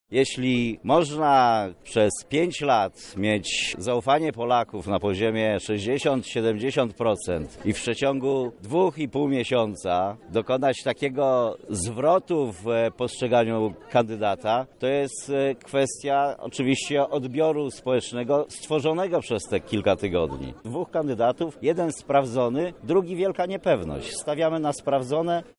Lubelscy sympatycy Platformy Obywatelskiej podczas wczorajszego wiecu wyrazili swoje poparcie dla starającego się o reelekcję Bronisława Komorowskiego.
Wydarzeniu towarzyszyły okrzyki Bronek musisz i Zwyciężymy.